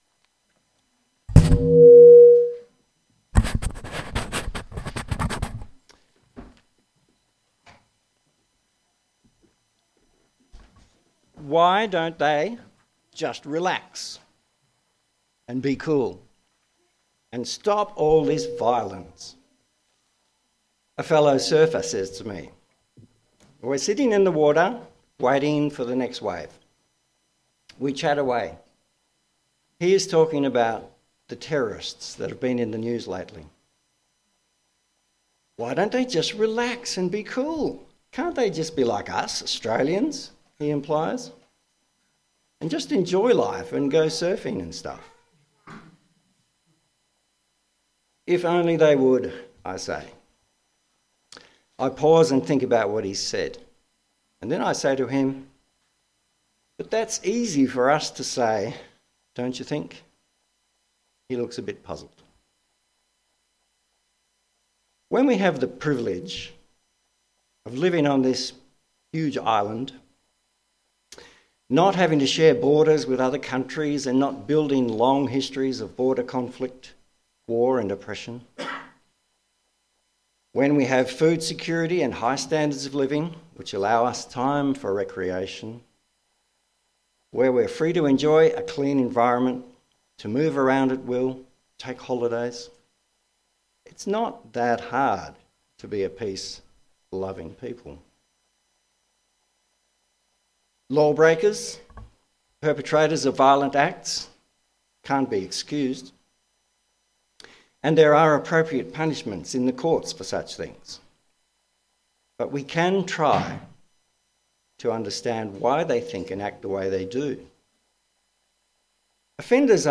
Passage: Matthew 13 Service Type: Sunday AM